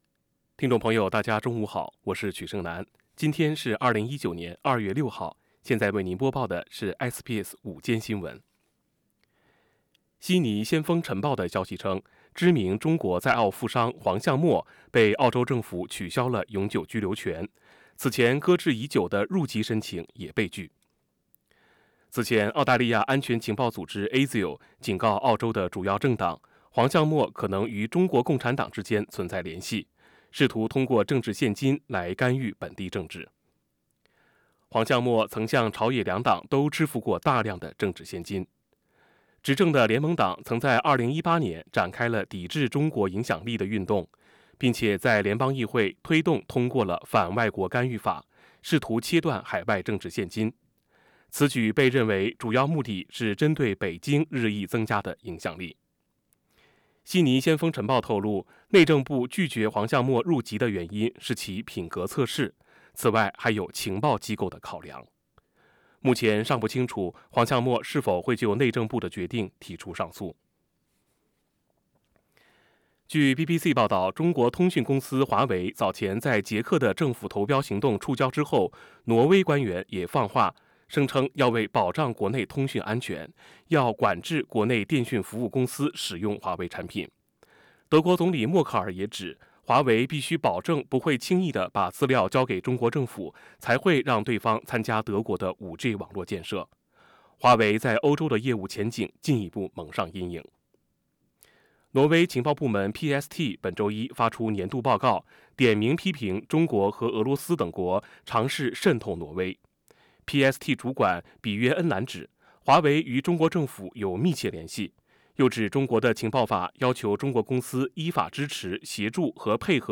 You are now with SBS Radio Mandarin Program streaming with SBS Chinese Midday News.
midday_news_feb_6.mp3